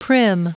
Prononciation du mot prim en anglais (fichier audio)
Prononciation du mot : prim